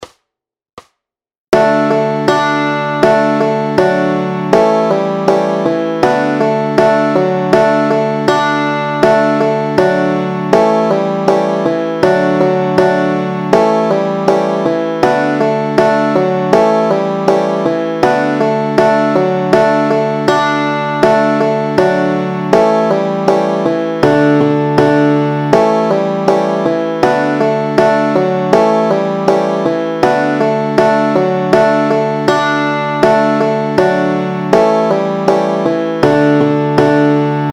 Aranžmá Noty, tabulatury na banjo
Formát Banjové album
Hudební žánr Lidovky